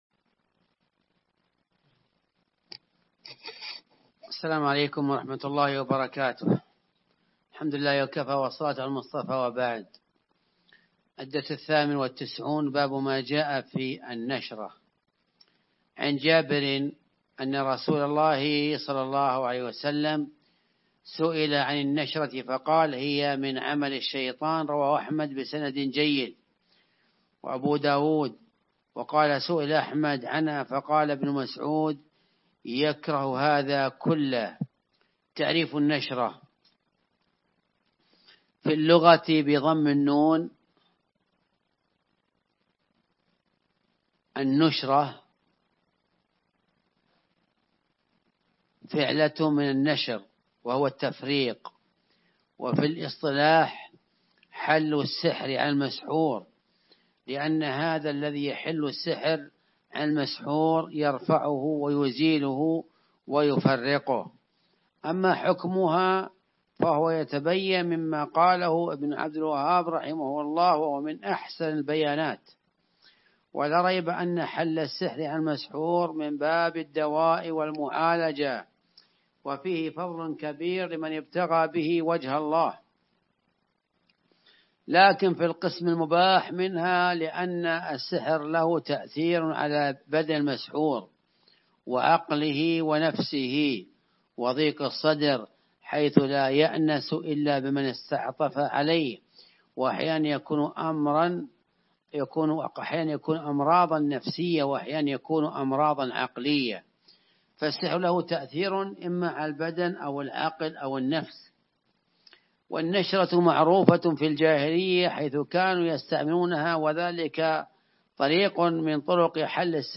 المحاضرة